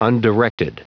Prononciation du mot undirected en anglais (fichier audio)
Prononciation du mot : undirected